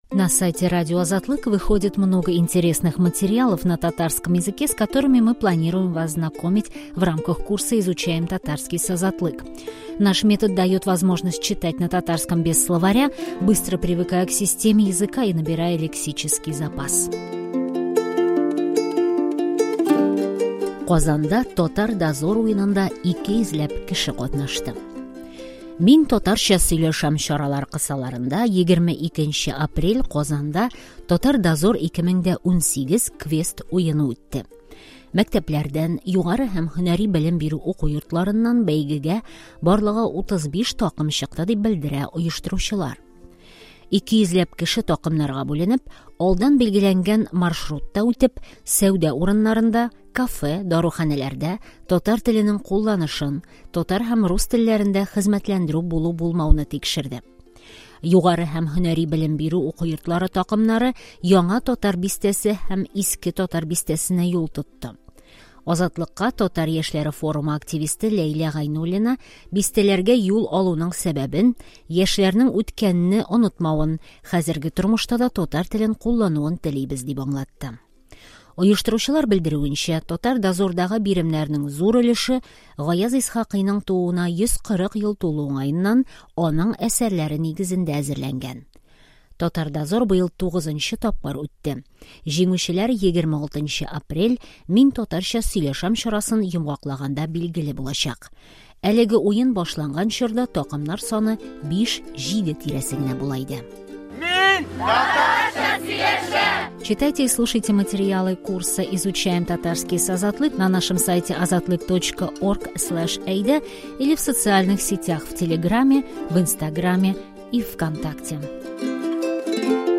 Также, обратите внимание, что специально для нашего проекта мы озвучили этот текст.